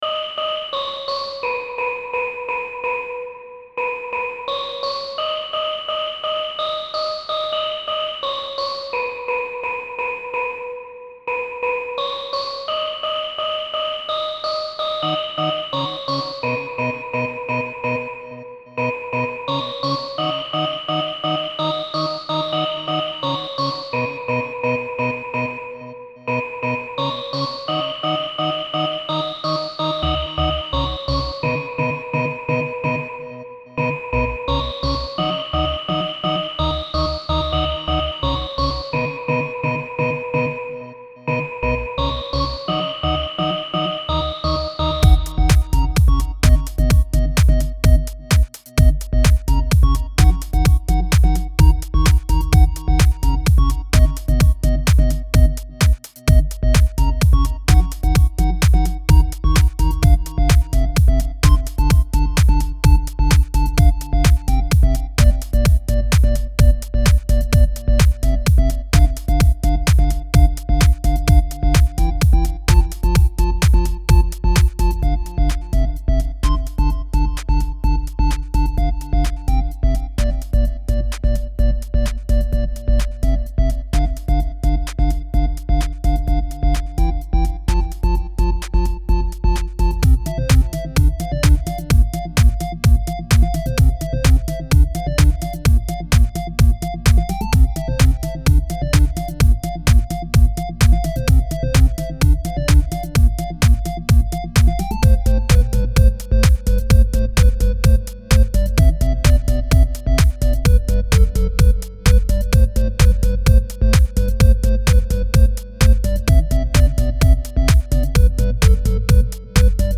Home > Music > Beats > Electronic > Bright > Running